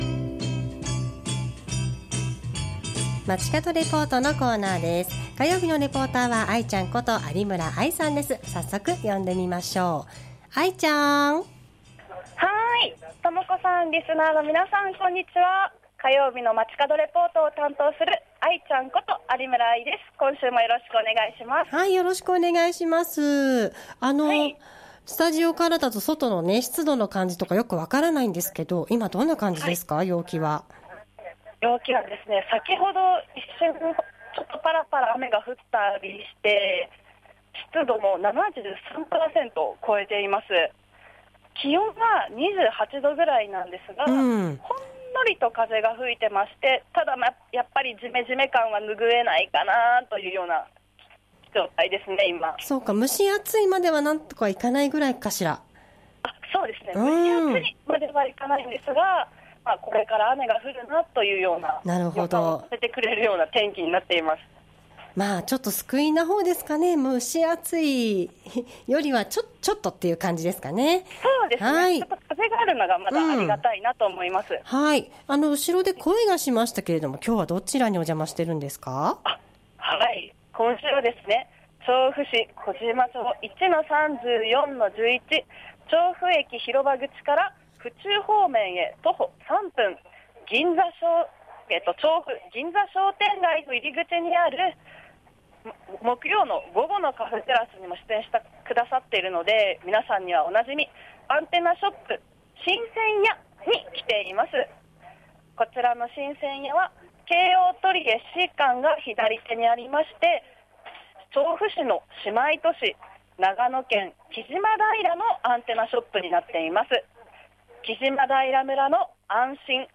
今週は、調布市の姉妹都市である木島平村のアンテナショップ、「新鮮屋」からお届けしました。